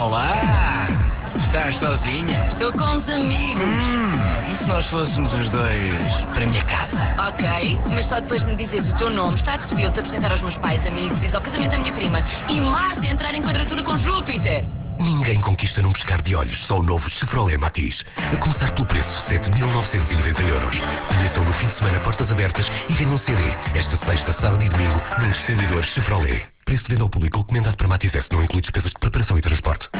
A marca Chevrolet está a entrar no Mercado Português e para tal em rádio estreou dia 12 de Maio uma campanha do Matiz com o slogan "O pequeno conquistador" (